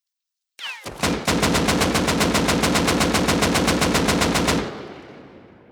Full Clip Spray Assault Rifle Sound Effect Free Download
Full Clip Spray Assault Rifle